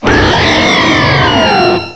cry_not_yveltal.aif